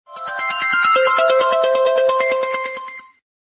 Darmowe dzwonki - kategoria SMS
Typowy dźwięk dla ramówek wiadomości ze świata.